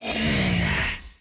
burer_aggressive_2.ogg